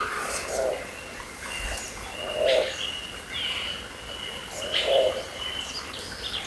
Nome científico: Nothoprocta cinerascens
Nome em Inglês: Brushland Tinamou
Localidade ou área protegida: Río Ceballos
Condição: Selvagem
Certeza: Observado, Gravado Vocal
Inambu-montaraz.mp3